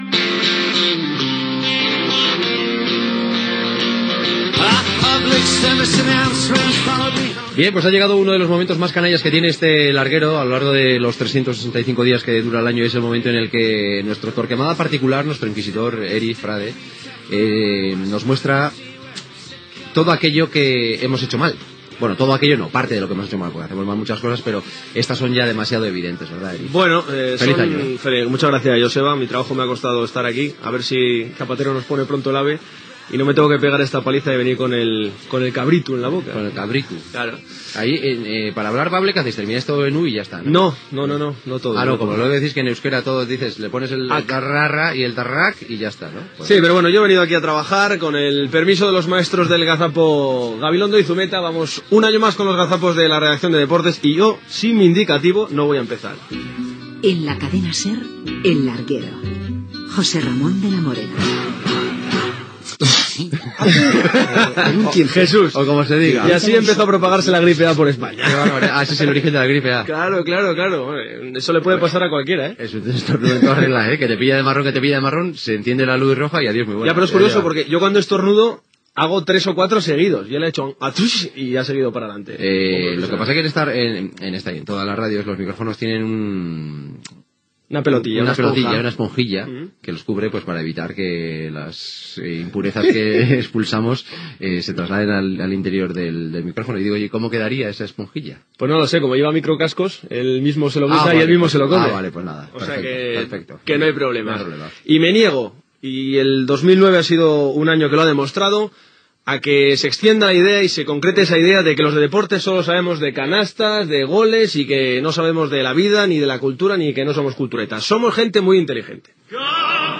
Recull d'errades i curiositats radiofòniques de la redacció d'esports de la Cadena SER
Esportiu